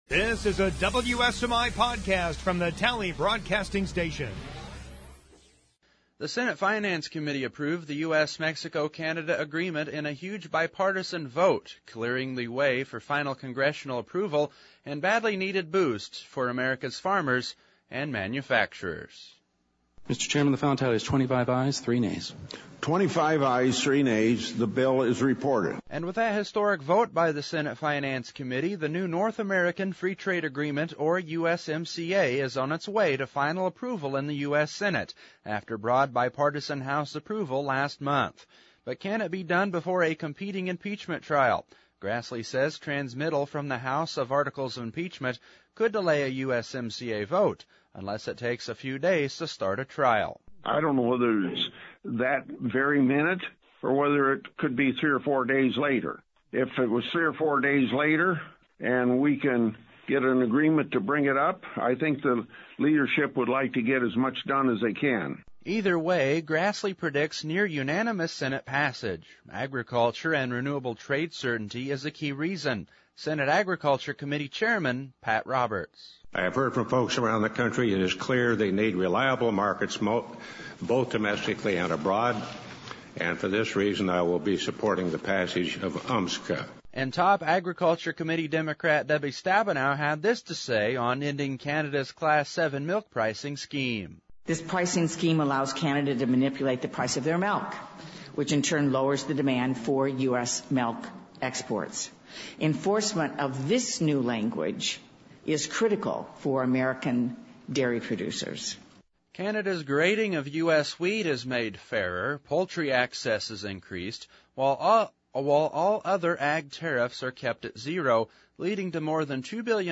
Senate Finance Committe Advances USMCA 25-3 2. ARC and PLC Deadlines Approaching 3. Interview w